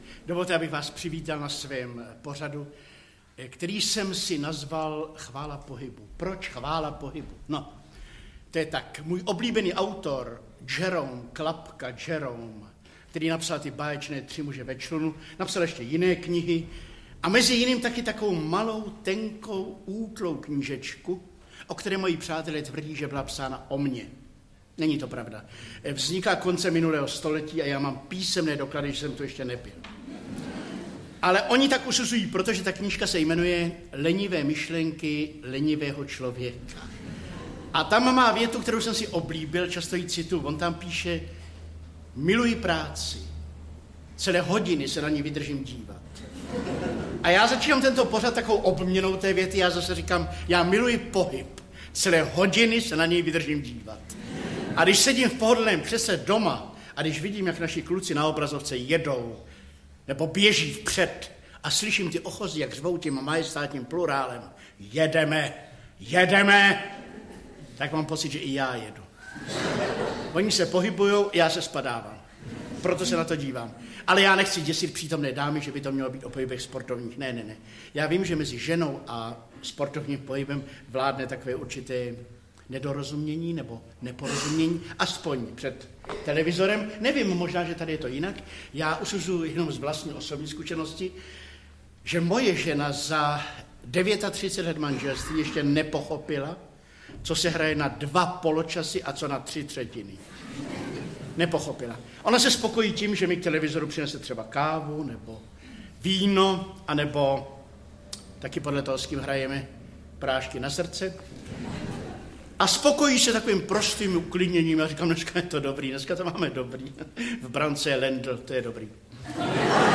Audiokniha Hýbu se, tedy jsem - obsahuje nahrávku živého vystoupení v Luhačovicích z roku 1987.